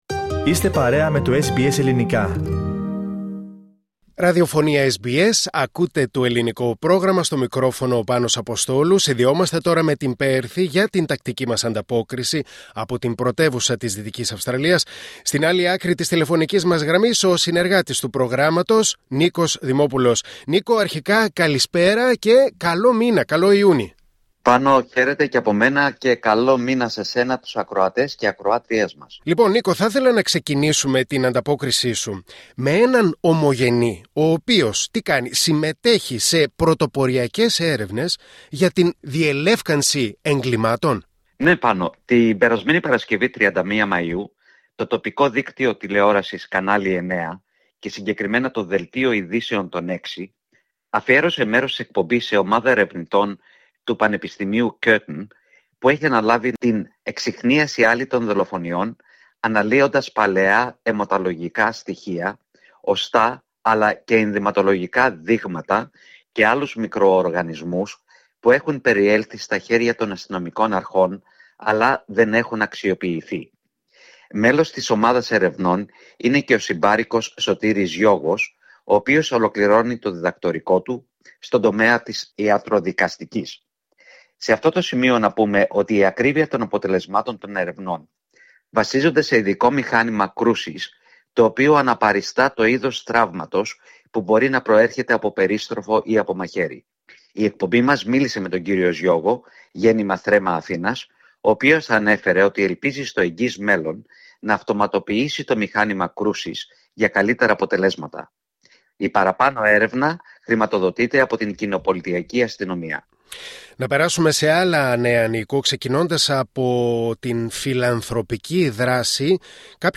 Ακούστε την εβδομαδιαία ανταπόκριση από την Πέρθη της Δυτικής Αυστραλίας